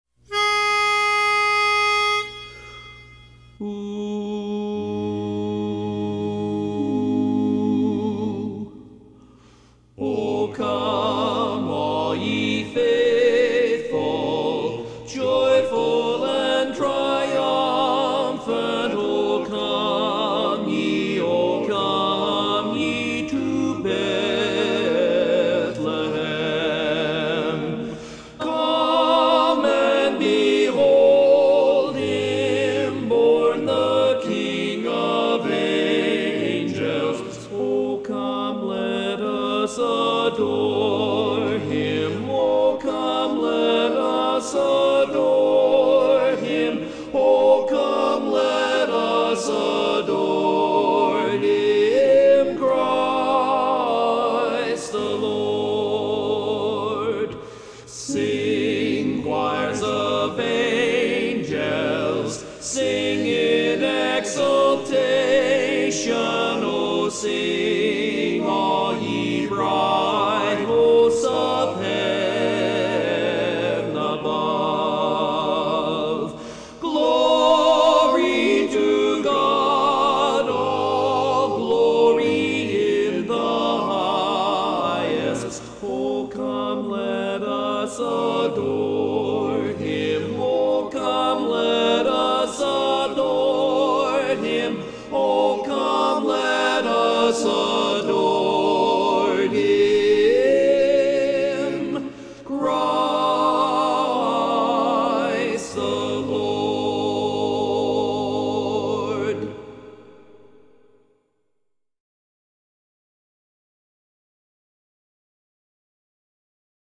Christmas Songs
Barbershop
Lead